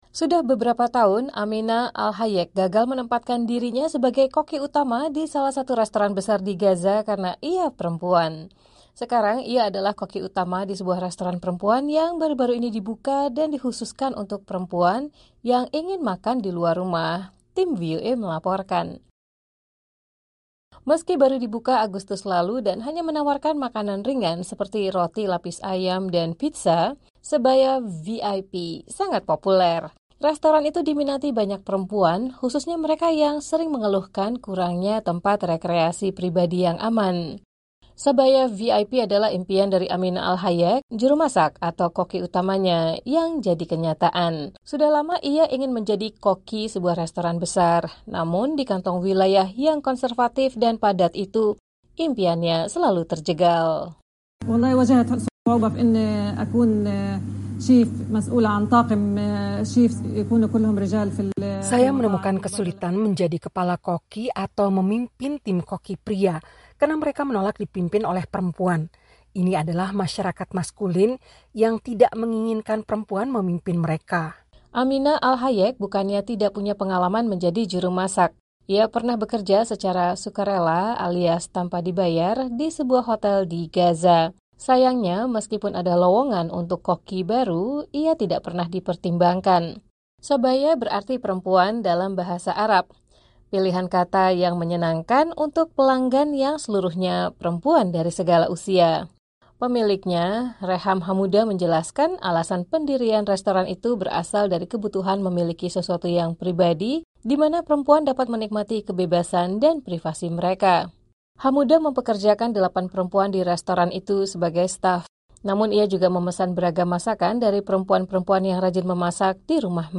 Tim VOA melaporkan.